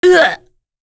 Morrah-Vox_Damage_01.wav